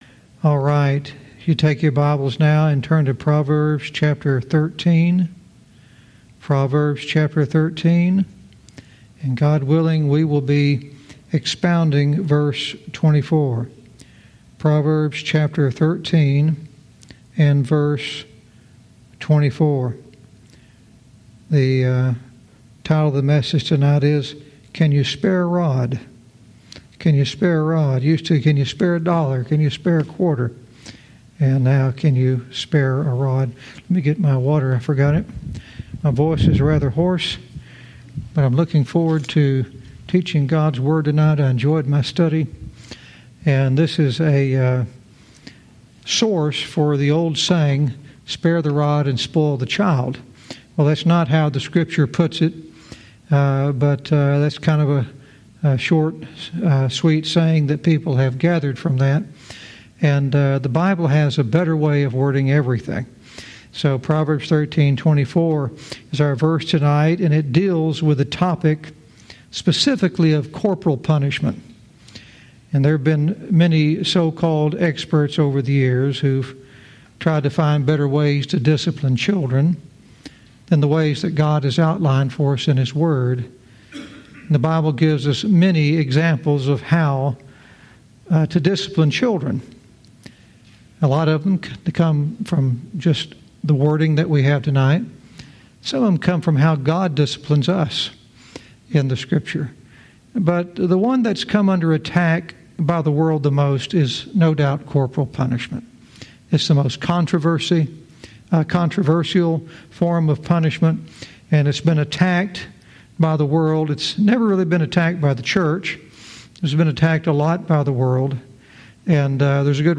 Verse by verse teaching - Proverbs 13:24 "Can You Spare a Rod?"